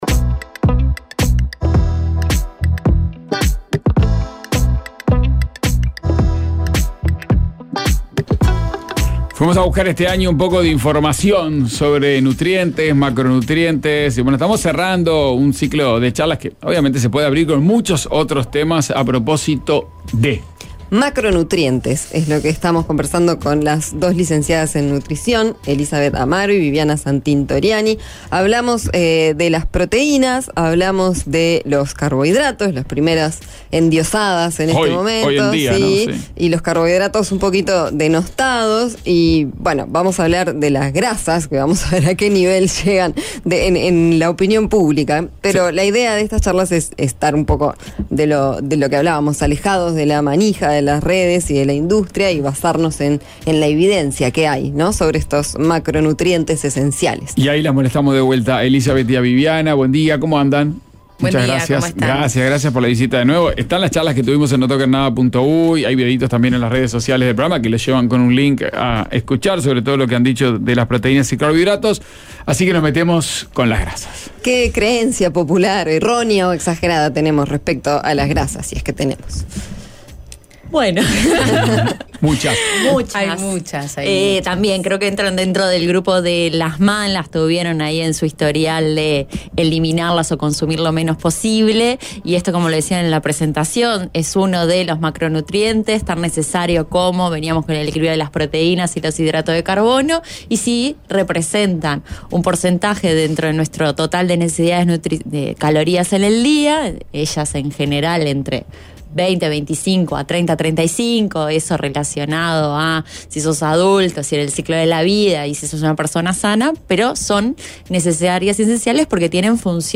Charlamos sobre eso y escuchamos dos temas en vivo.